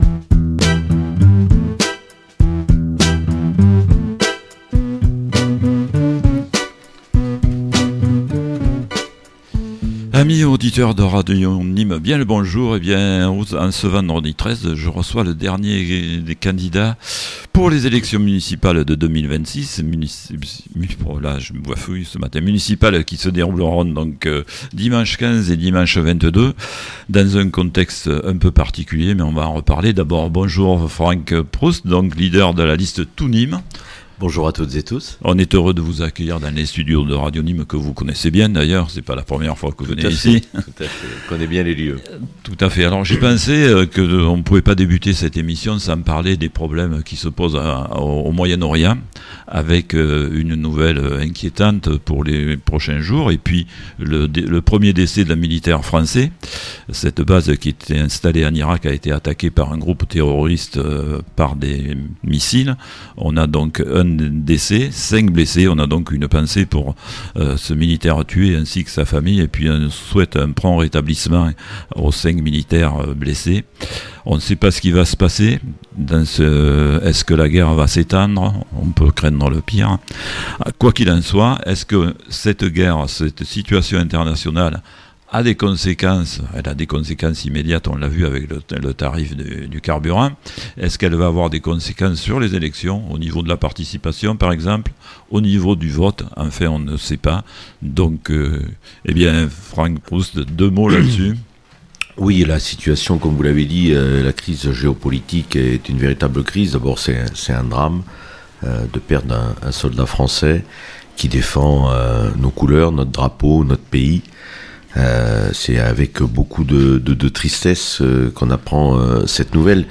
Municipales 2026 - Entretien avec Franck PROUST (Tout Nîmes) - EMISSION DU 13 MARS